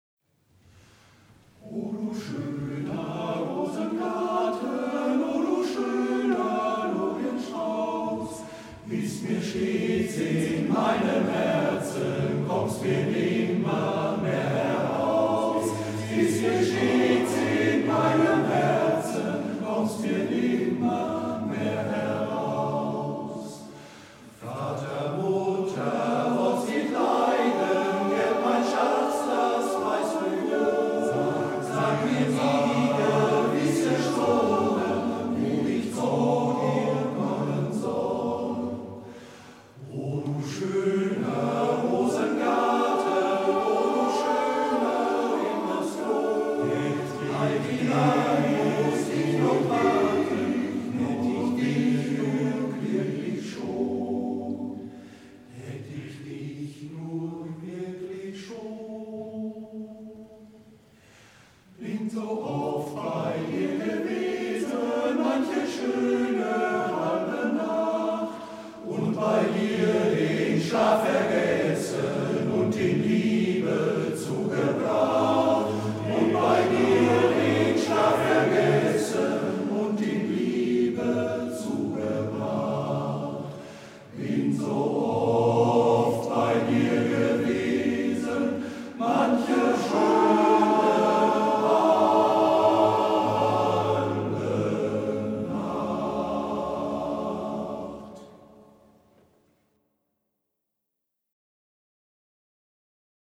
Zur Karte der Stelenstandorte "O du schöner Rosengarten" gesungen vom MGV "Cäcila" Volkringhausen e.V. Um das Lied zu hören, bitte auf unser Bild klicken.